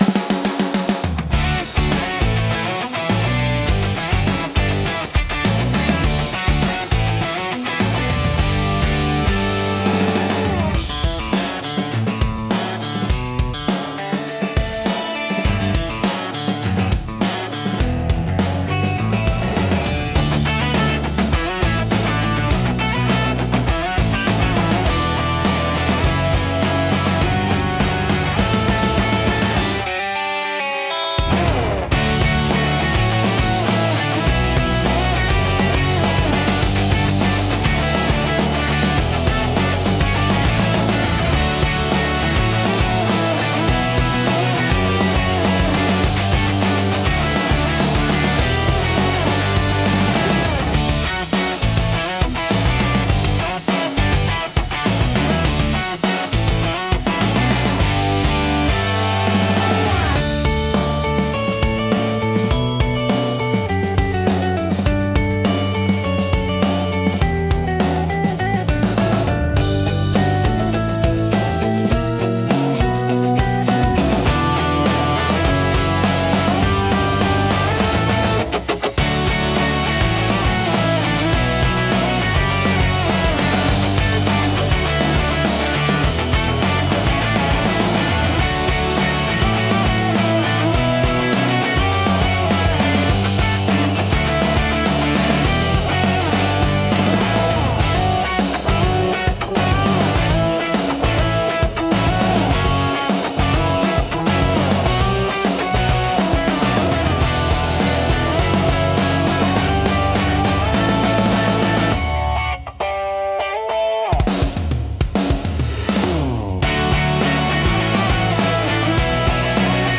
感情の高まりやポジティブな気分を引き出しつつ、テンポ良く軽快なシーンを盛り上げる用途が多いです。